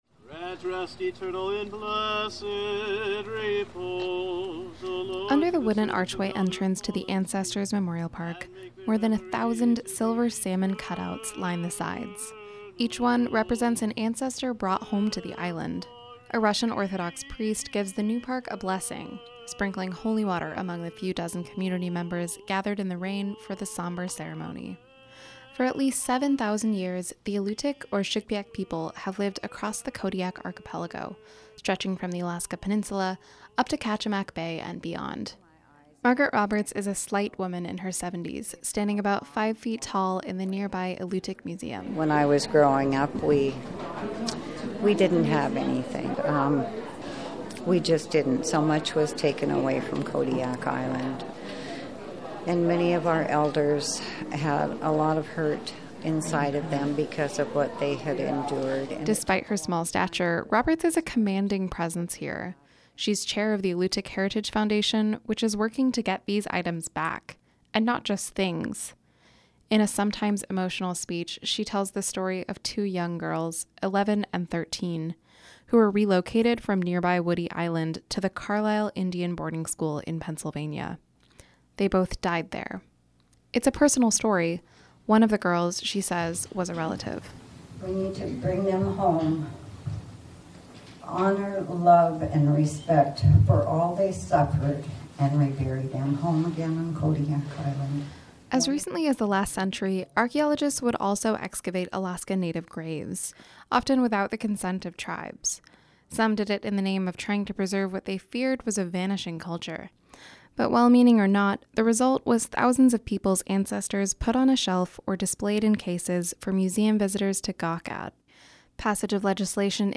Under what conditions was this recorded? A Russian Orthodox priest gives the new park a blessing, sprinkling holy water among a few dozen community members gathered in the rain for the somber ceremony.